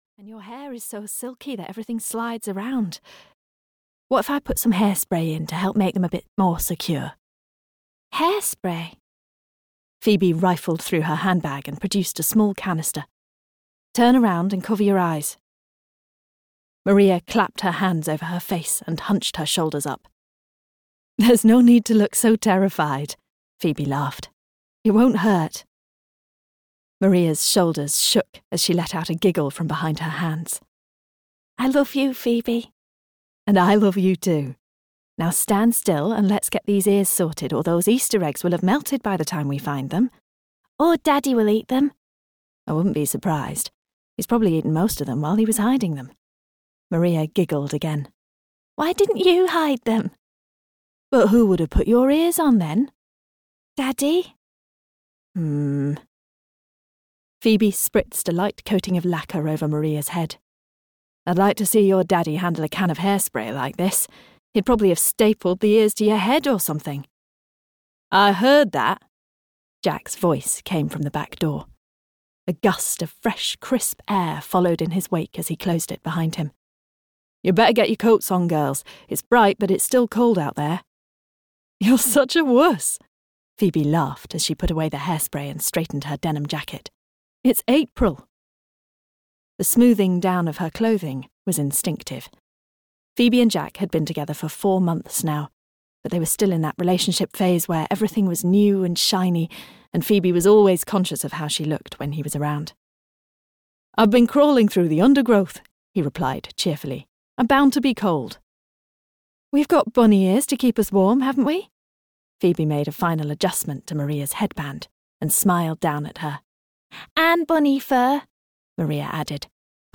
The Spring of Second Chances (EN) audiokniha
Ukázka z knihy
the-spring-of-second-chances-en-audiokniha